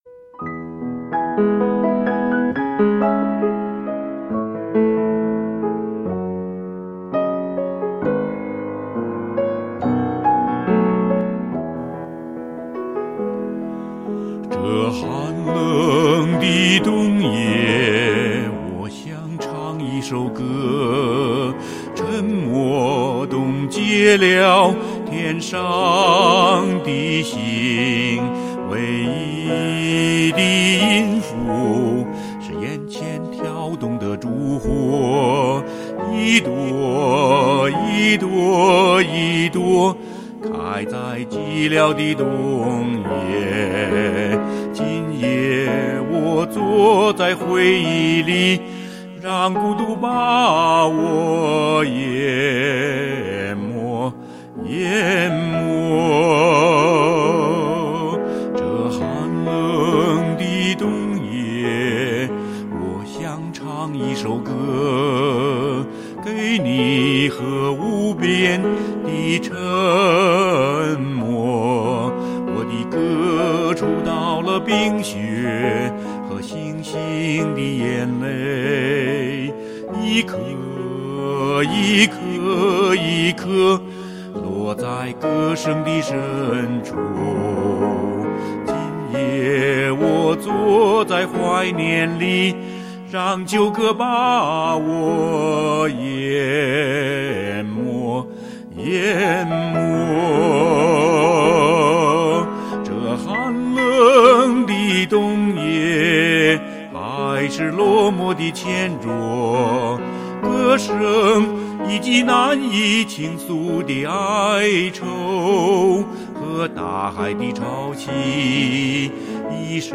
重新又录了这首歌。